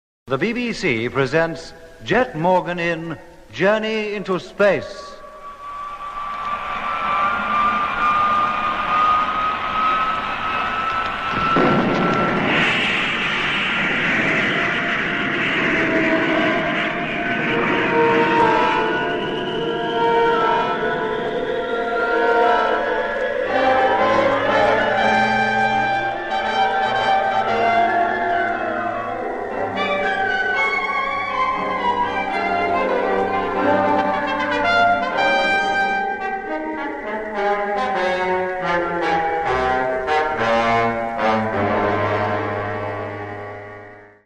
Genre: SF-ruimtevaart
Maar al vrij snel werd er een 8-koppig orkest in de studio gezet dat de muziek live speelde.